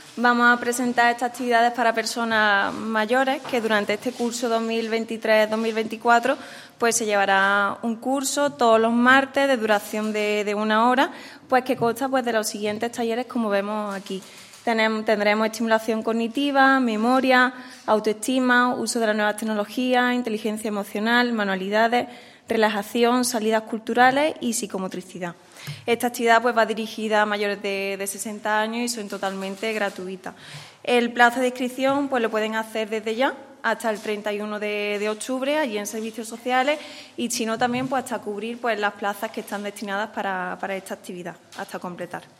La concejal delegada de Familia y Mayores, María Sierras, ha presentado hoy en rueda de prensa el Programa Municipal de Actividades para Personas Mayores que todos los años se impulsa desde el Ayuntamiento de Antequera con el objetivo de tratar de mejorar la vida y el ocio de las personas mayores de 60 años.
Cortes de voz